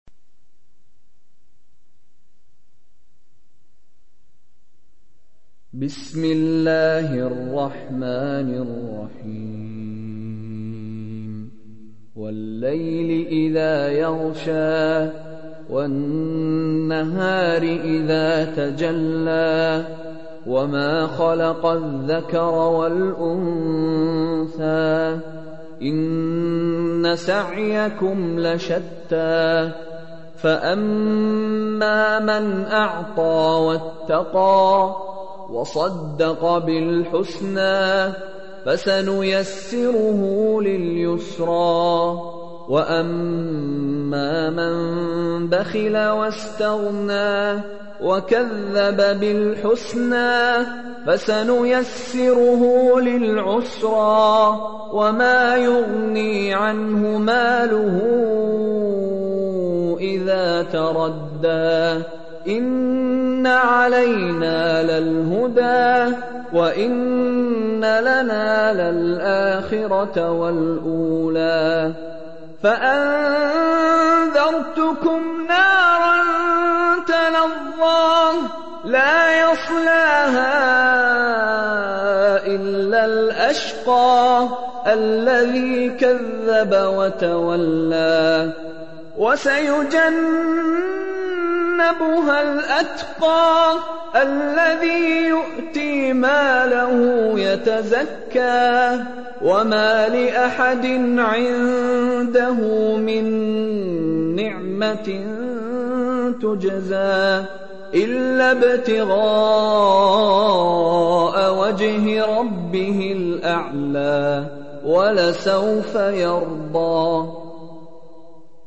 Чтение Корана > МИШАРИ РАШИД